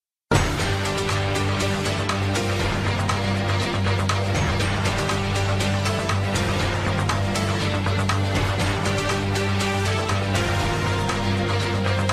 Voici 3 exemples de générique :